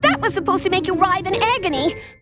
Worms speechbanks
OOPS.wav